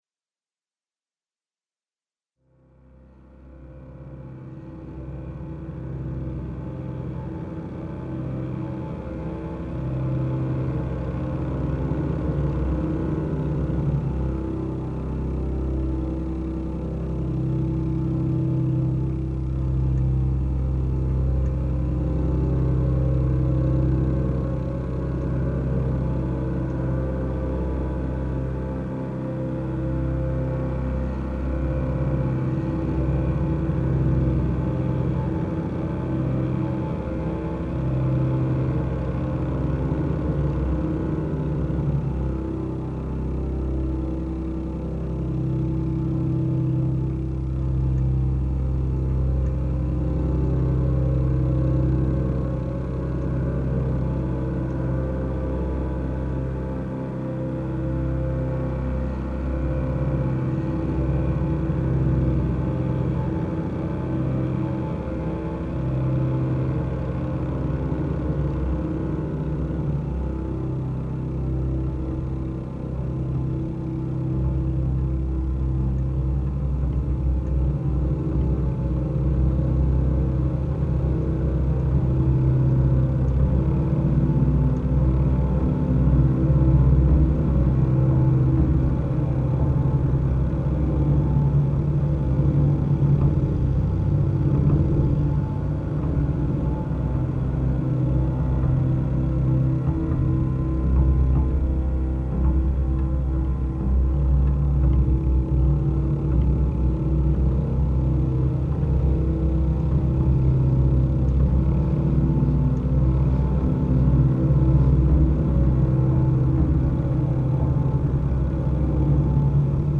cello, trombone, bugle, khan, bamboo flutes, ocarina,
whistles and sound toys, voice, tube, and hand percussion